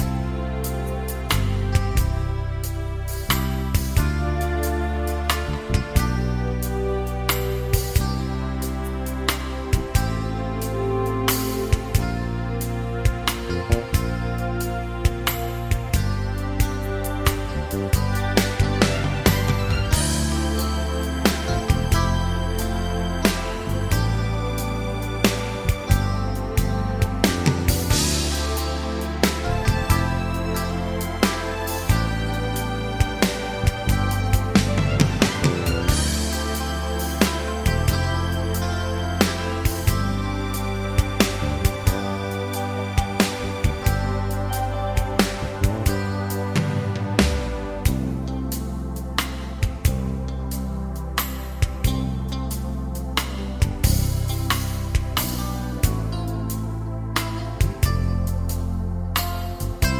no piano Soft Rock 4:25 Buy £1.50